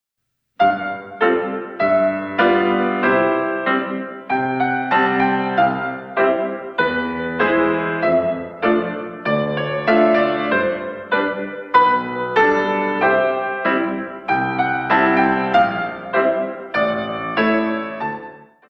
In 2